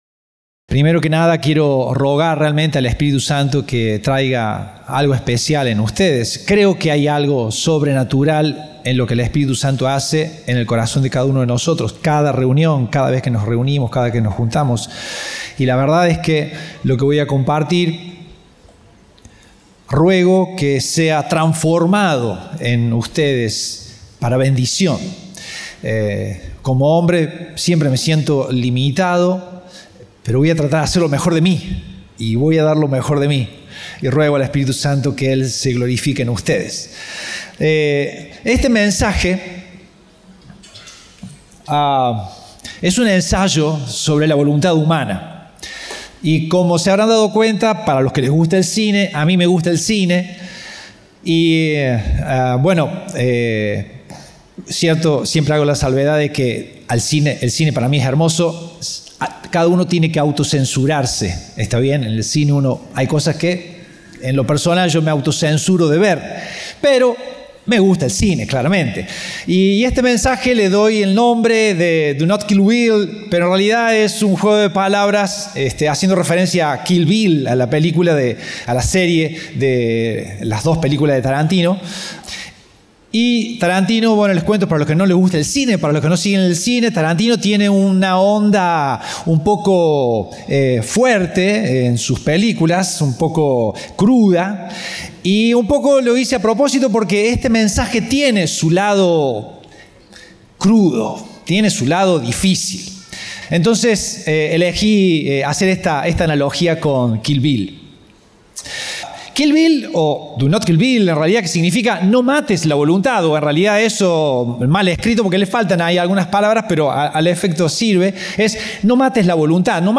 Compartimos el mensaje del Domingo 7 de Diciembre de 2025